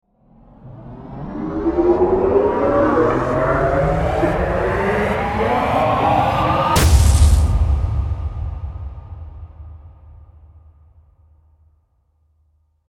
Rise-speed-up-and-hit-sound-effect-cinematic-impact-audio.mp3